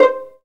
PIZZ VLN C4.wav